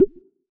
Bubble Pop Shoot v4.wav